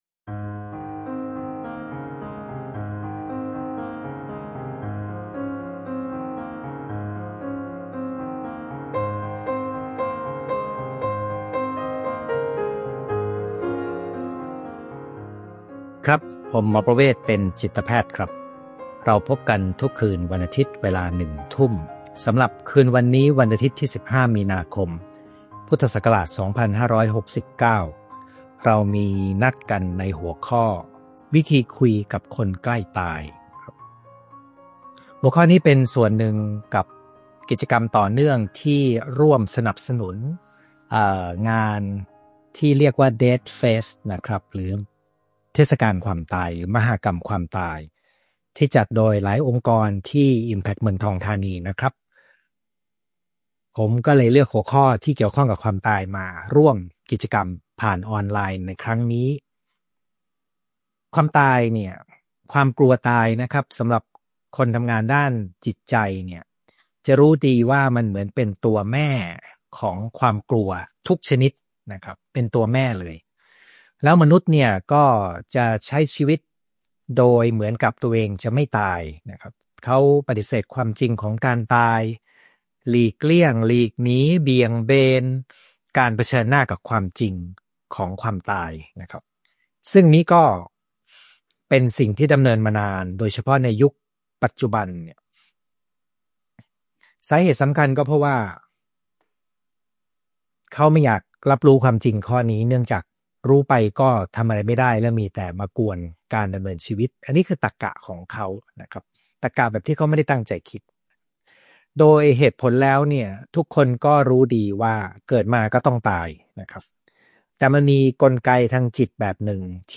ไลฟ์ประจำวันอาทิตย์ที่ 15 มีนาคม 2569 เวลาหนึ่งทุ่ม สนใ…